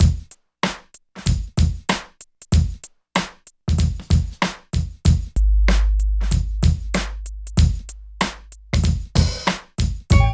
43 DRUM LP-L.wav